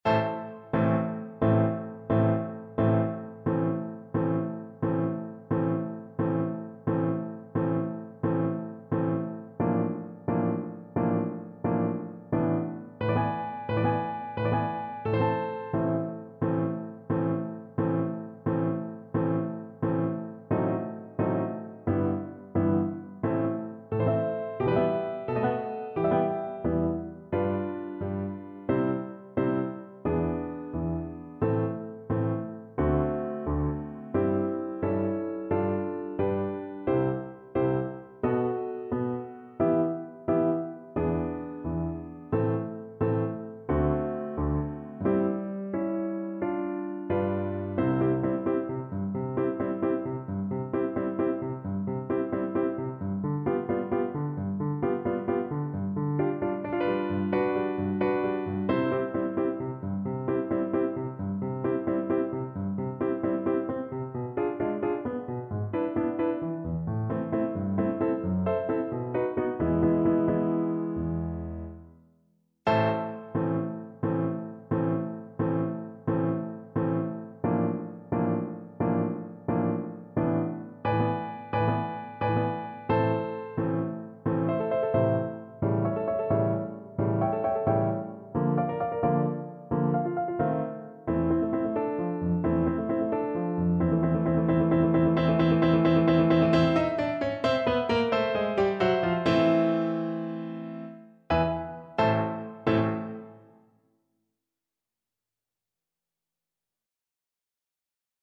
Play (or use space bar on your keyboard) Pause Music Playalong - Piano Accompaniment Playalong Band Accompaniment not yet available transpose reset tempo print settings full screen
Flute
6/8 (View more 6/8 Music)
A minor (Sounding Pitch) (View more A minor Music for Flute )
Allegro ma non troppo (.=88) (View more music marked Allegro)
Classical (View more Classical Flute Music)